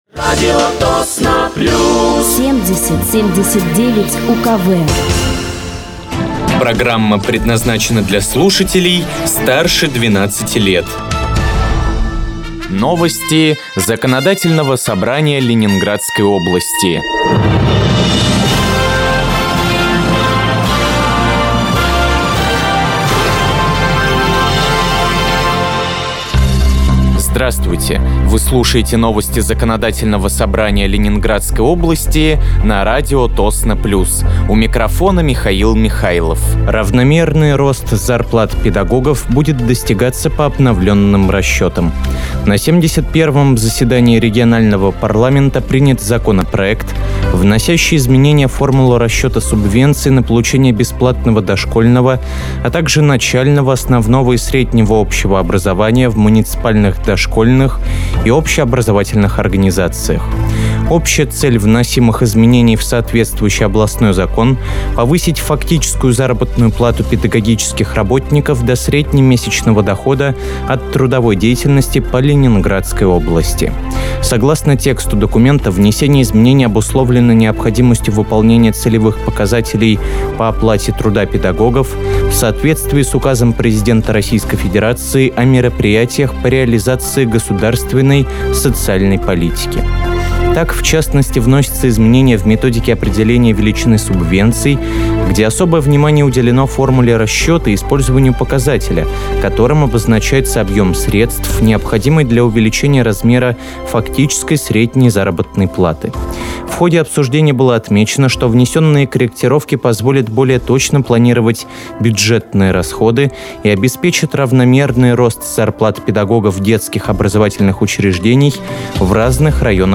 Выпуск новостей Законодательного собрания Ленинградской области от 25.11.2025
Вы слушаете новости Законодательного собрания Ленинградской области на радиоканале «Радио Тосно плюс».